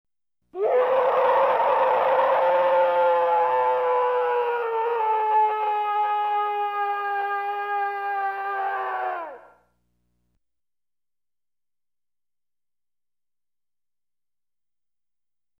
Scary Sounds - 47 - Monster Roar Type